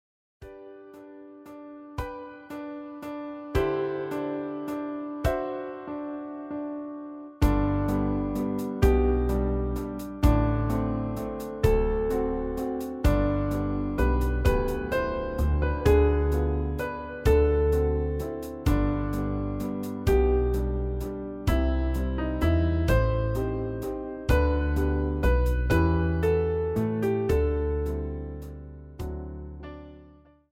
Quality mp3 file arranged for piano and accompaniment